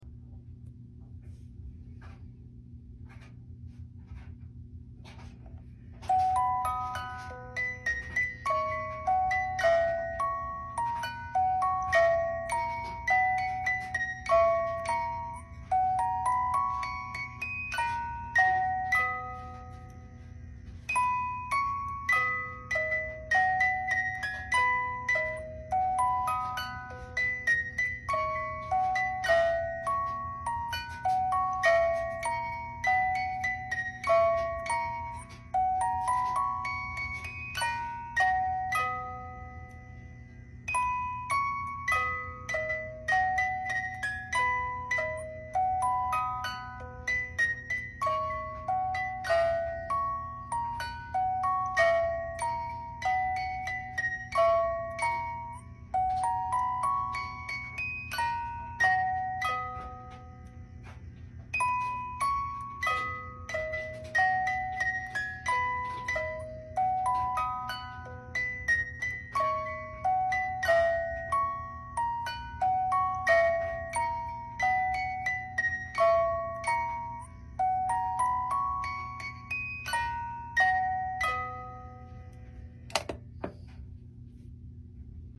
Music Box